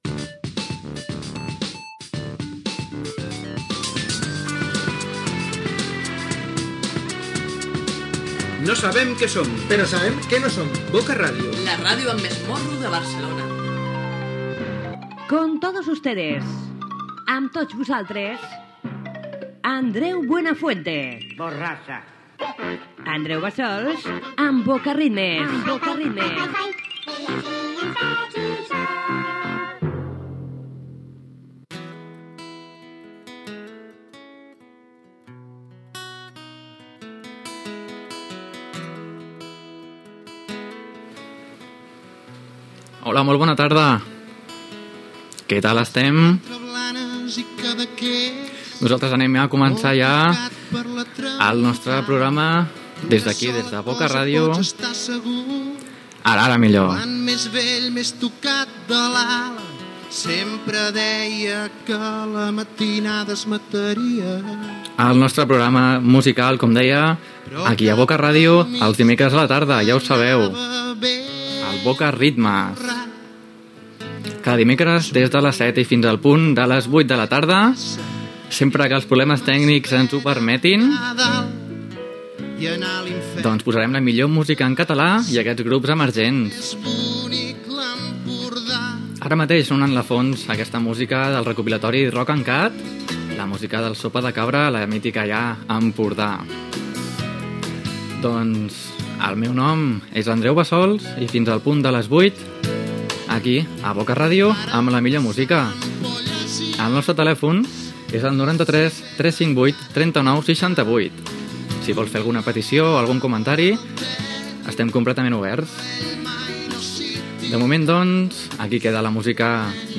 Indicatiu de la ràdio, careta del programa, salutació i presentació d'un tema musical
Musical
FM